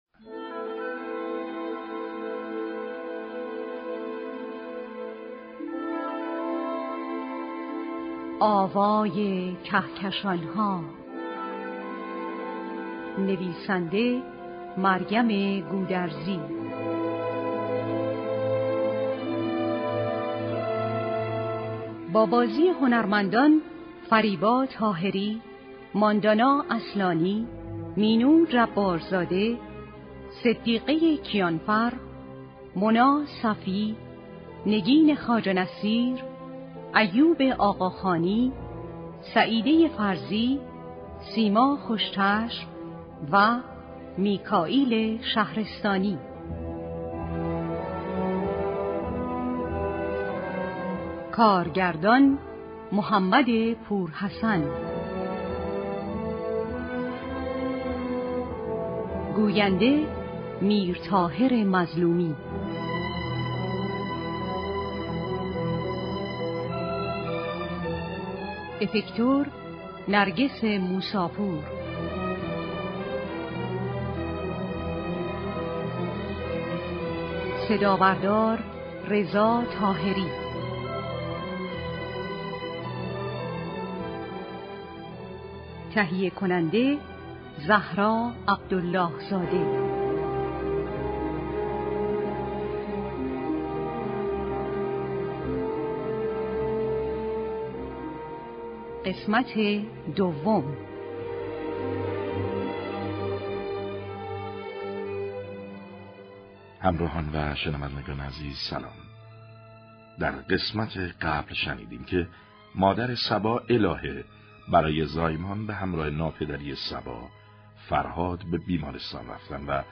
دوشنبه سوم دی ماه ، شنونده نمایش رادیویی